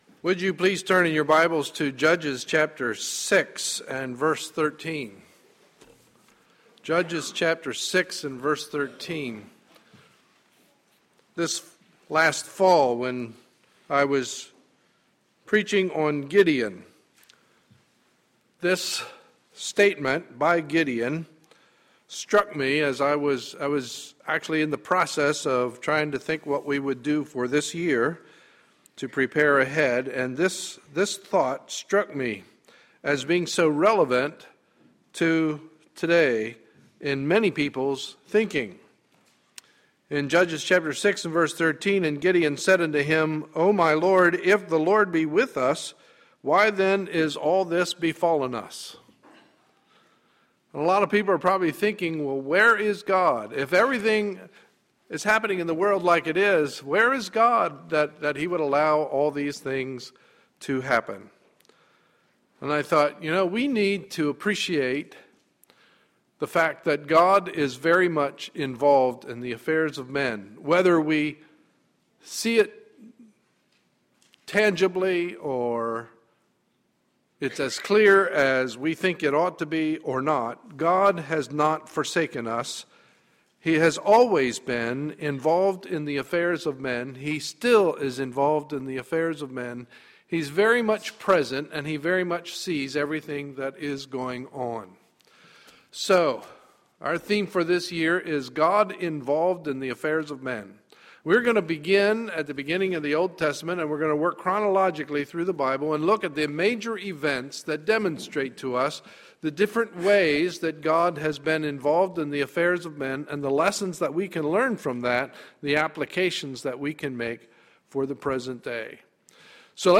Sunday, January 6, 2013 – Morning Message